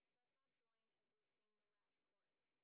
sp30_train_snr10.wav